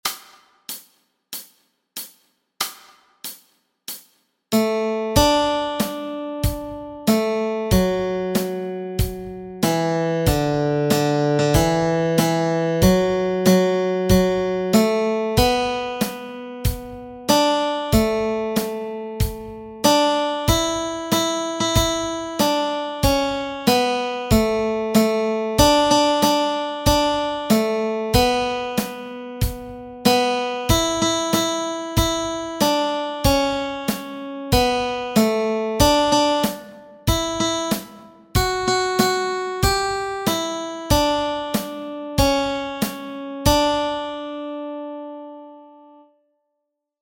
Melody Track
Behold-Behold-melody.mp3